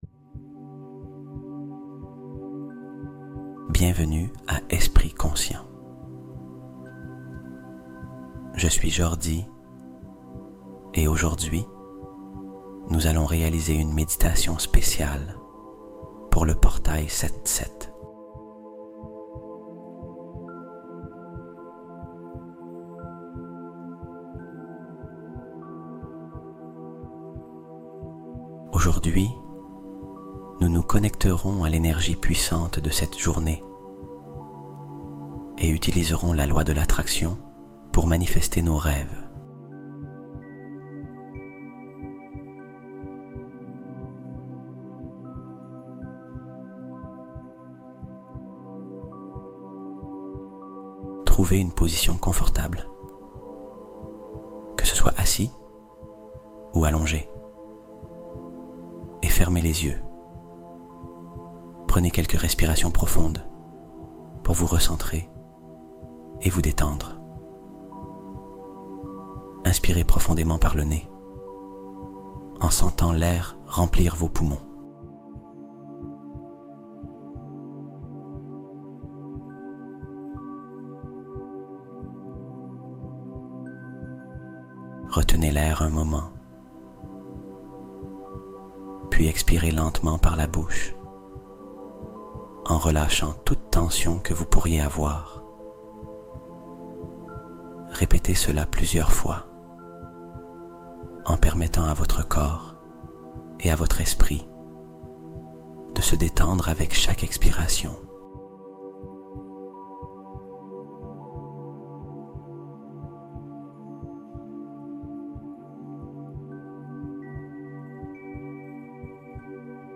Le Portail 7/7 S'Ouvre MAINTENANT : Manifeste Tes Rêves Avec La Fréquence Sacrée 1111 Hz